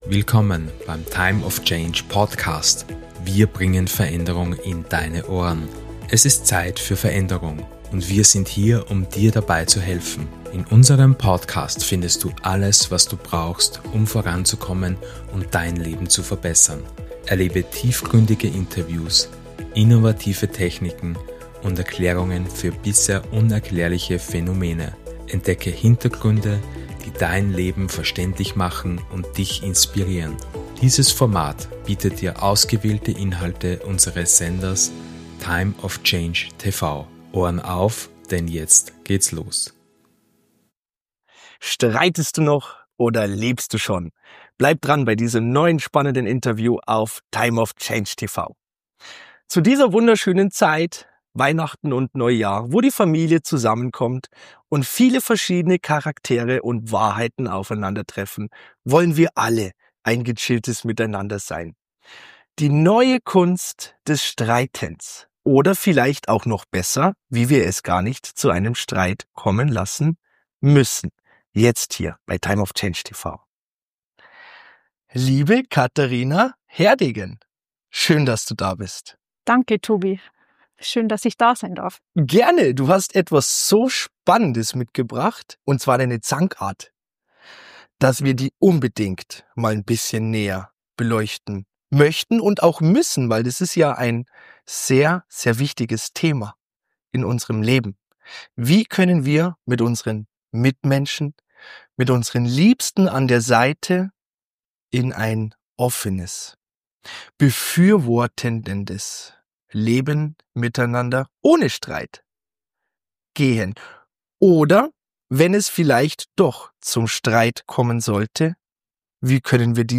In diesem fesselnden Interview begeben wir uns in die Welt der Konfliktlösung und harmonischen Kommunikation.